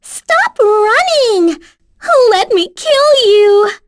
Pansirone-Vox_Skill1.wav